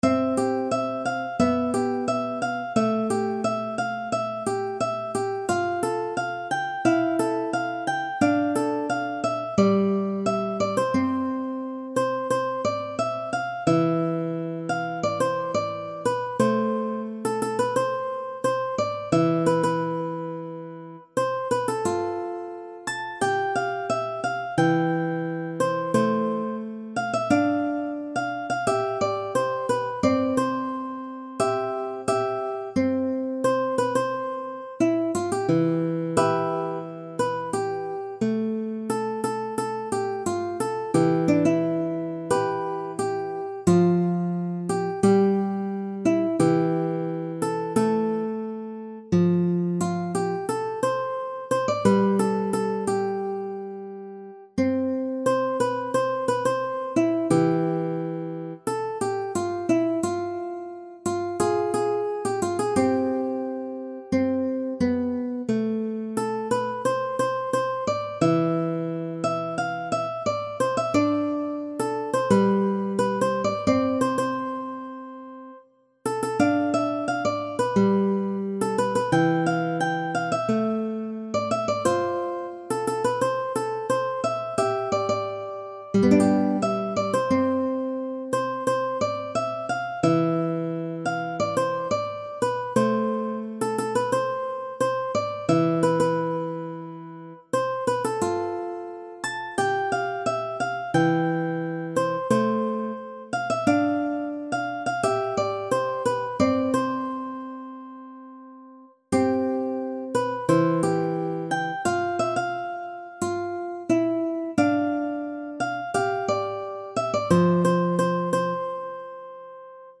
J-POP / ポップス / 女性グループ
アップした楽譜は原曲の特徴や魅力を損なわず、ギター初〜中級の方向けにギターソロで弾けるようにアレンジしました。
PC演奏（楽譜をそのままMP3にエクスポート）ですが